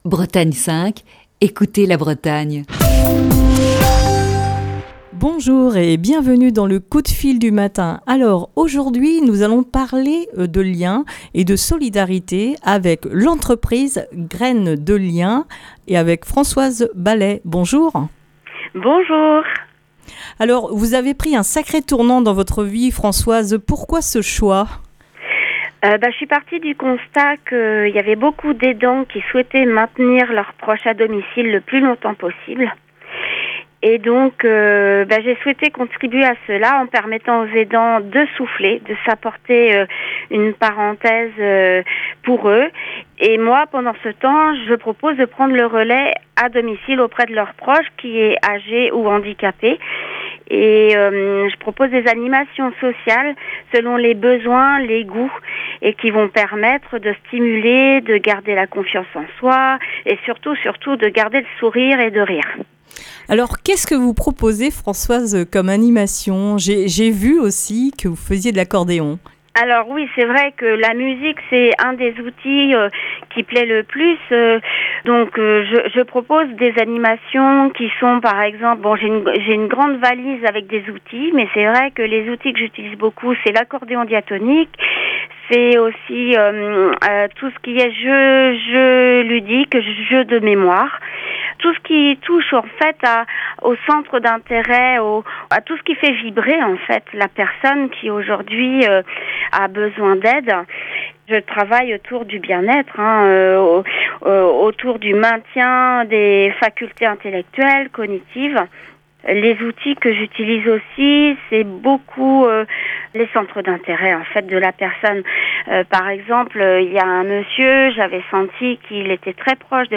Ce mardi dans le coup de fil du matin